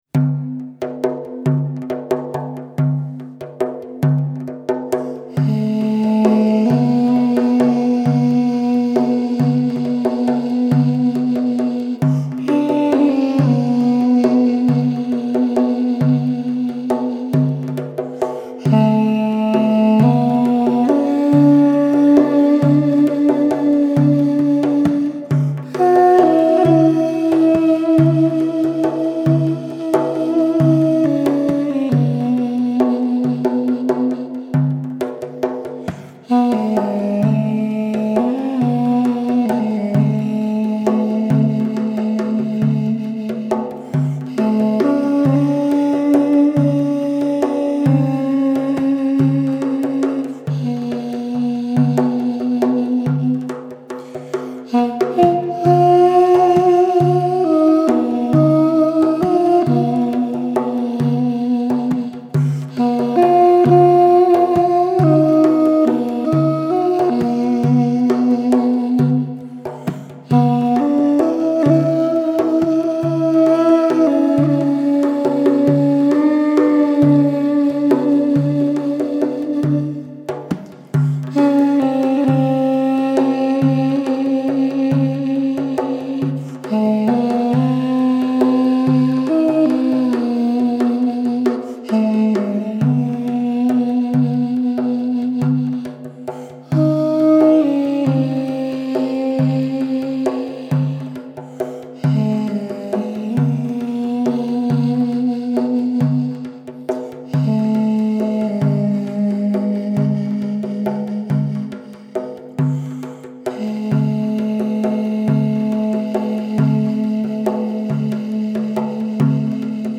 Possum Hall Studios
In this improvisation
Armenian duduk
tar (framedrum)